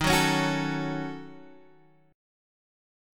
D#m7b5 chord